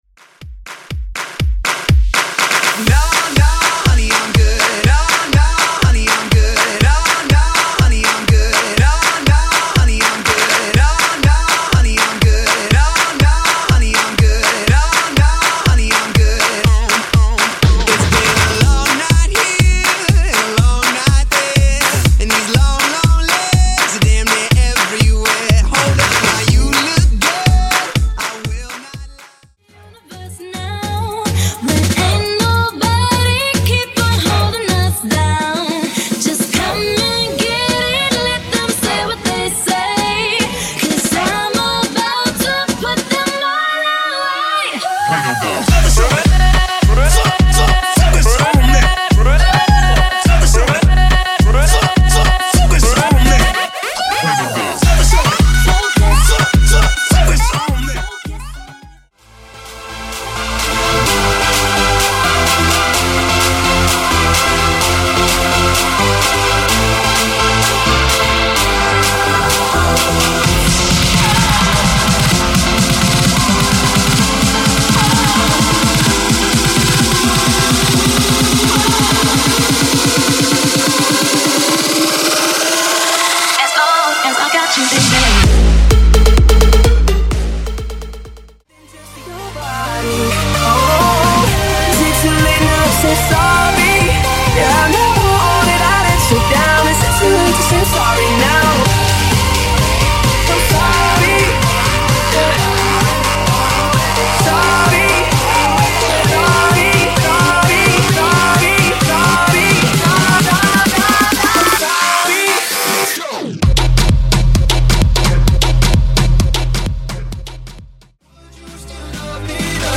Genre: MASHUPS
Clean BPM: 126 T